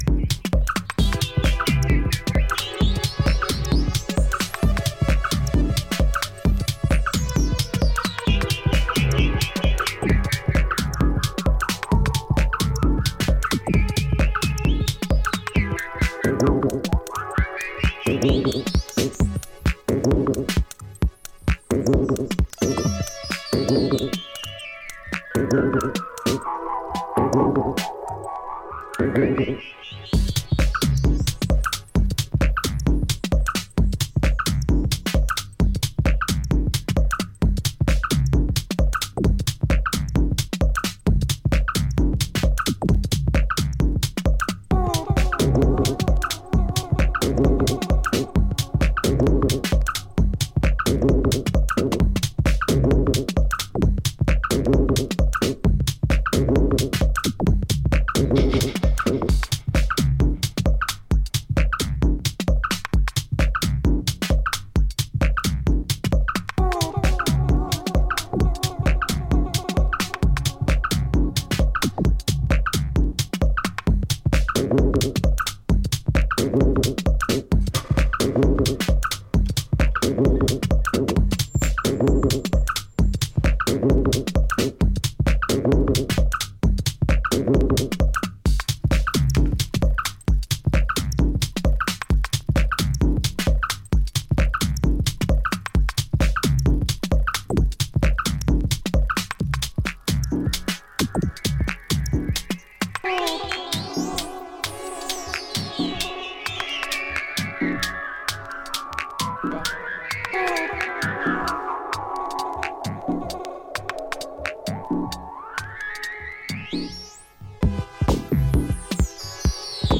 弾性高く忙しないグルーヴとスペーシーシンセ、そしてそれらの余白が素晴らしいバランスを保った